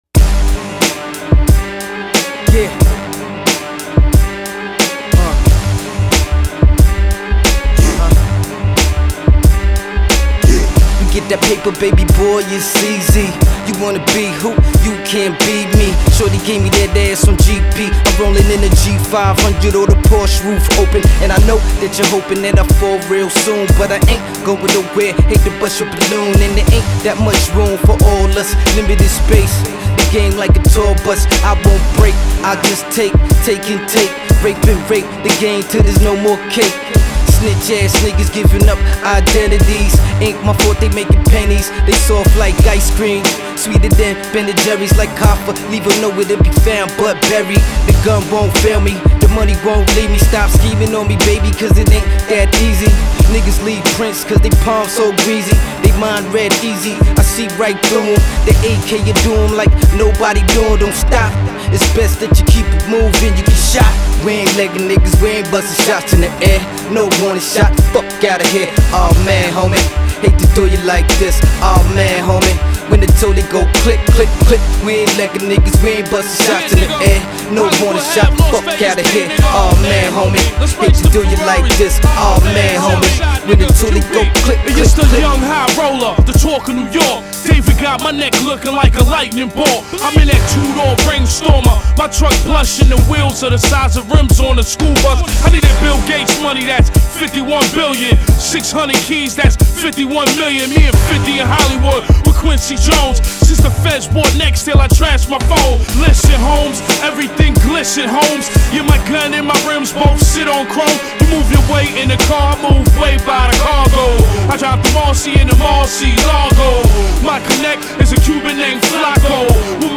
Назад в (rap)...